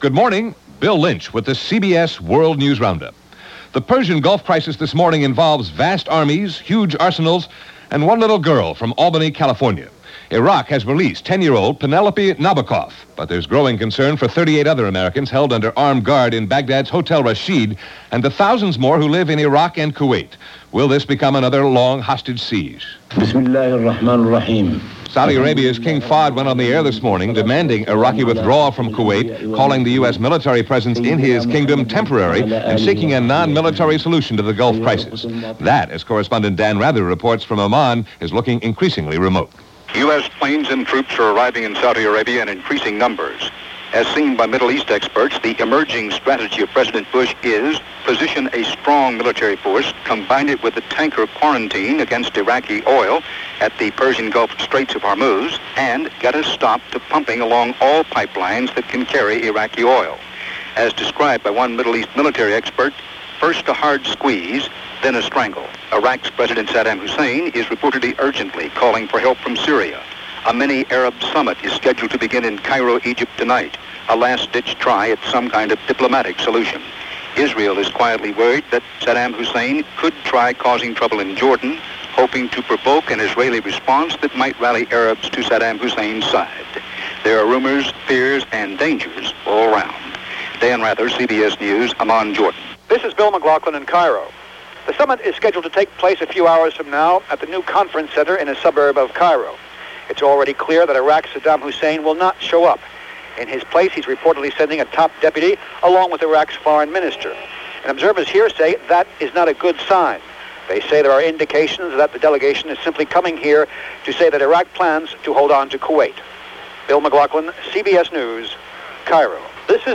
August 9, 1990 – CBS World News Roundup – Gordon Skene Sound Collection –